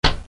Clic bas sec
Bruit de clic tonal faible.